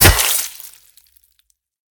generalswing.ogg